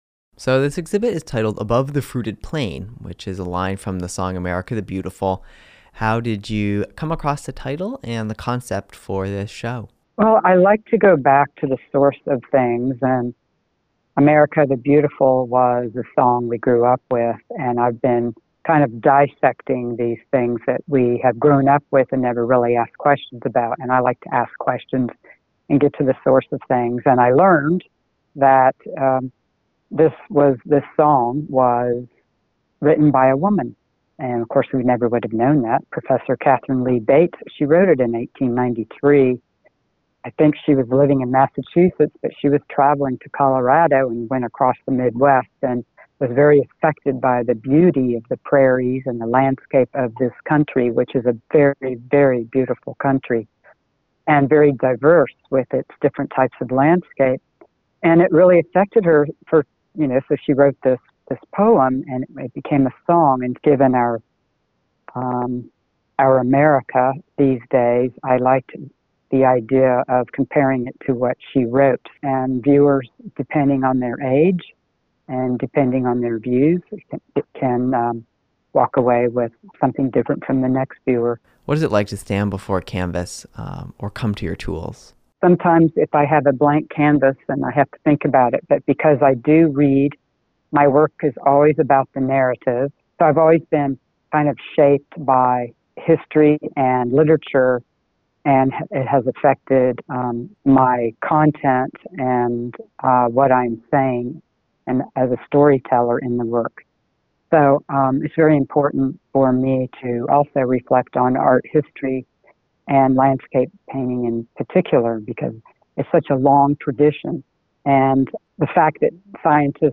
News Brief